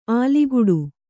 ♪ āliguḍu